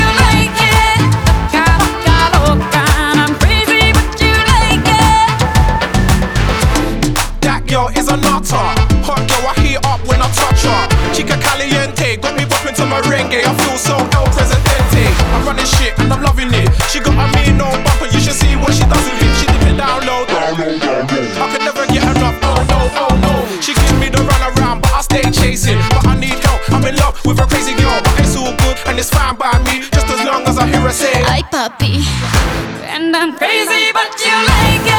Pop Latino Latin